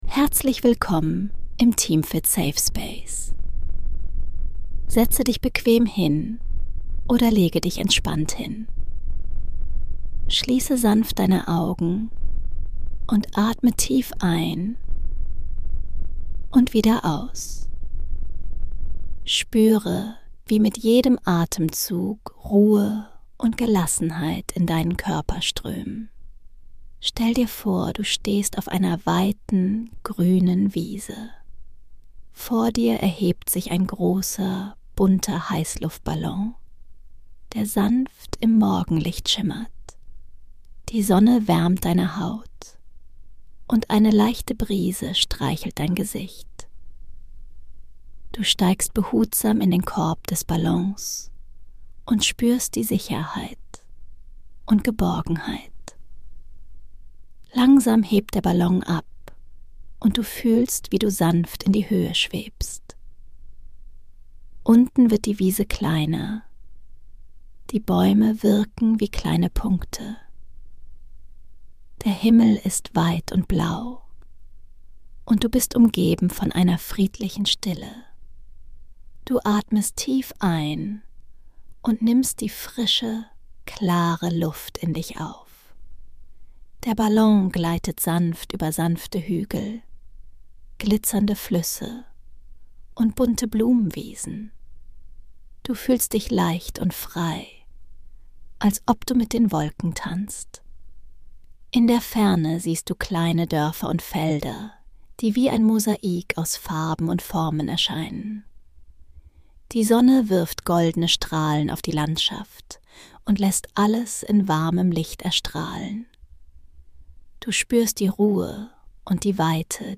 Entspanne dich bei dieser geführten Traumreise und erlebe einen